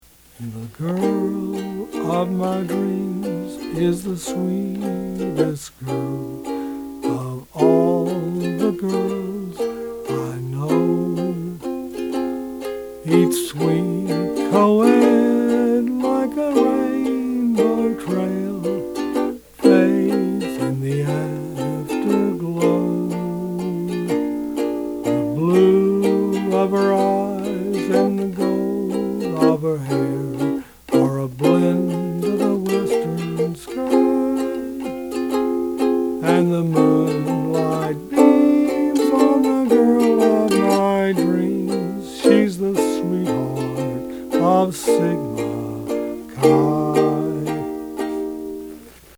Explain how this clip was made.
Please ignore any sour notes.